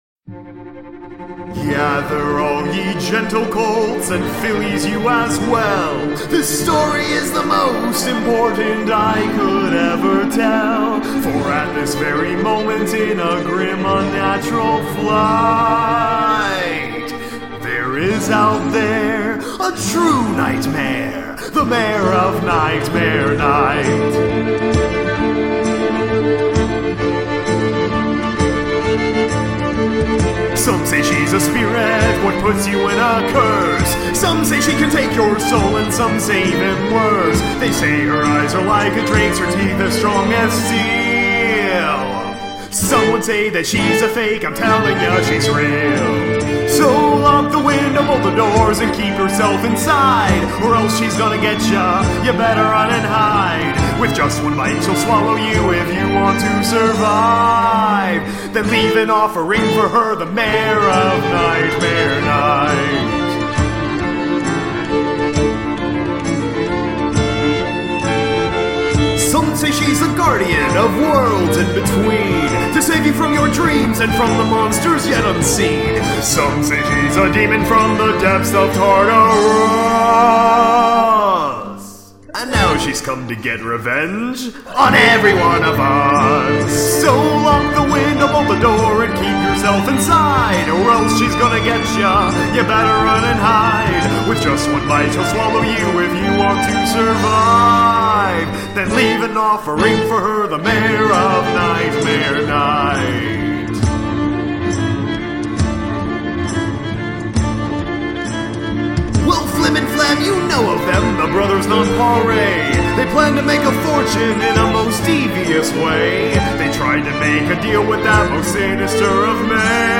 I mean, just listen to all that evil laughter.